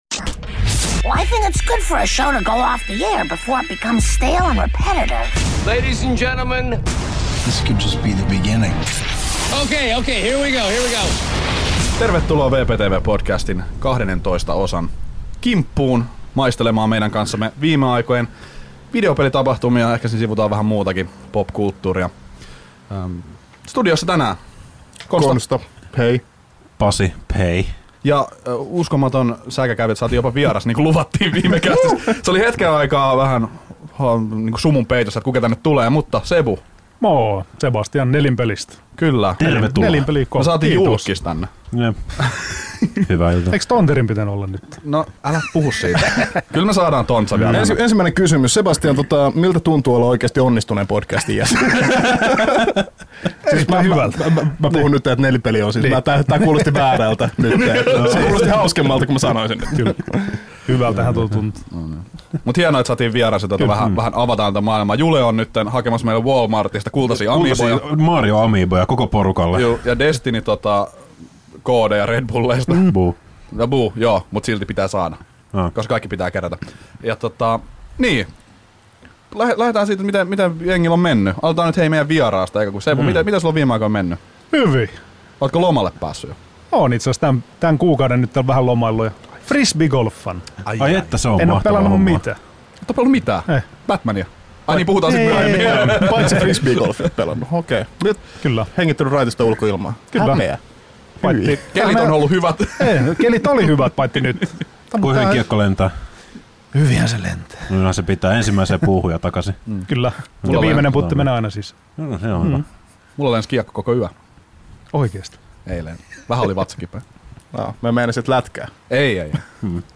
VPTV:n (ulko)studiossa on vieraita!